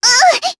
Rodina-Vox_Damage_jp_02.wav